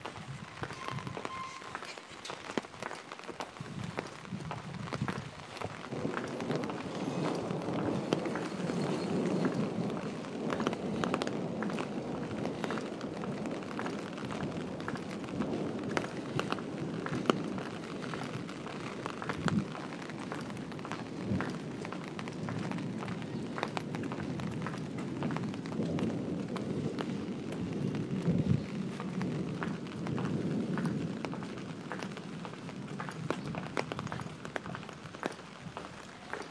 More Thunder Sounds.